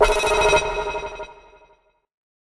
دانلود آهنگ هشدار موبایل 24 از افکت صوتی اشیاء
جلوه های صوتی